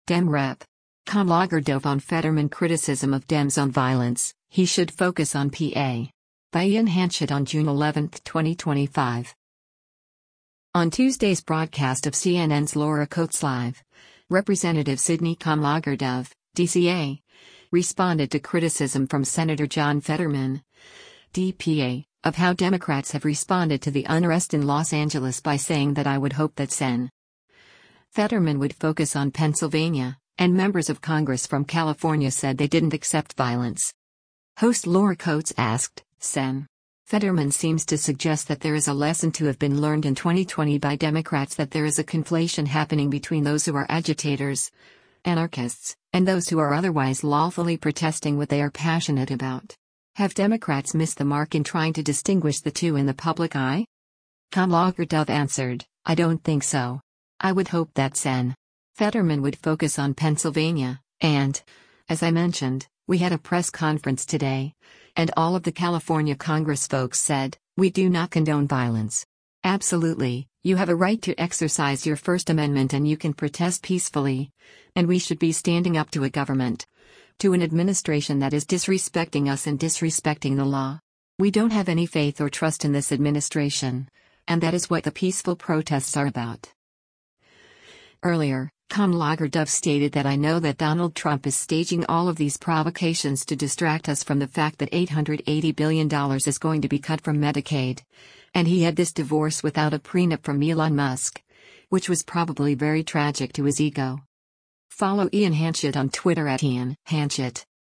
On Tuesday’s broadcast of CNN’s “Laura Coates Live,” Rep. Sydney Kamlager-Dove (D-CA) responded to criticism from Sen. John Fetterman (D-PA) of how Democrats have responded to the unrest in Los Angeles by saying that “I would hope that Sen. Fetterman would focus on Pennsylvania,” and members of Congress from California said they didn’t accept violence.